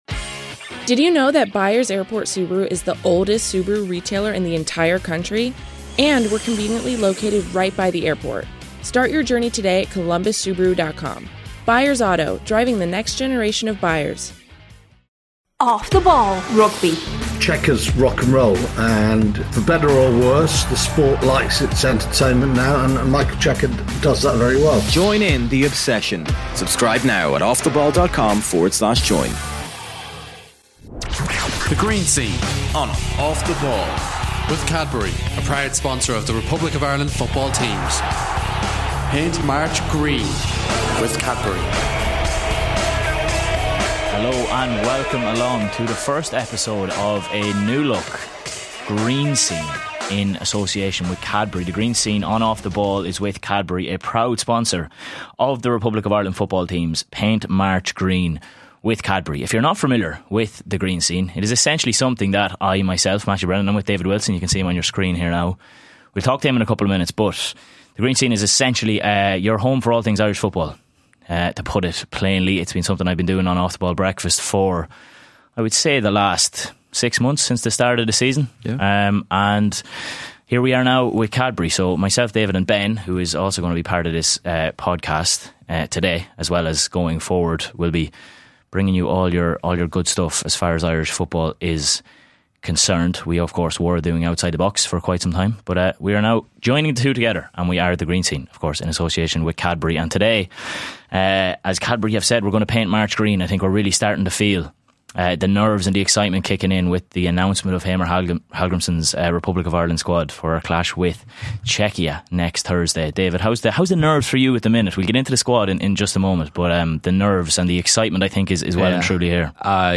Also hear from Thomas Tuchel after he names his England squad.
34:15 Thomas Tuchel INTERVIEW